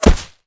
bullet_impact_grass_08.wav